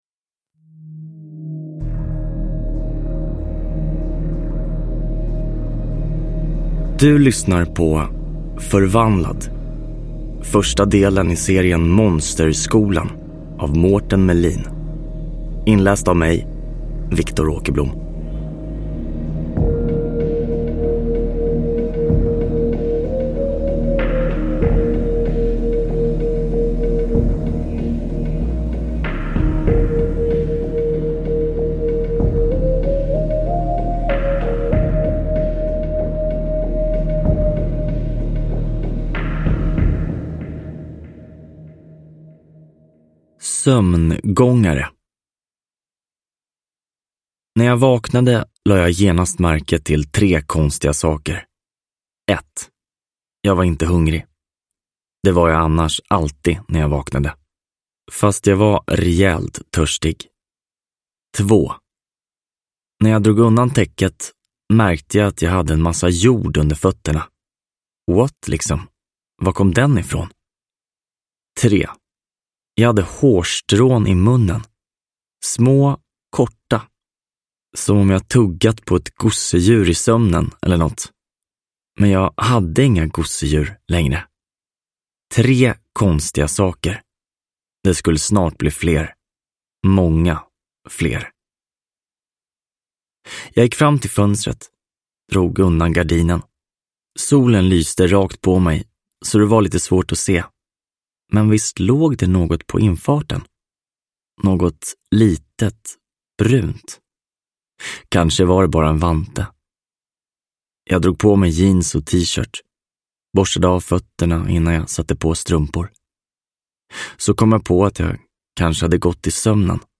Förvandlad – Ljudbok – Laddas ner